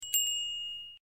cam_snap_2.ogg